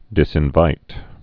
(dĭsĭn-vīt)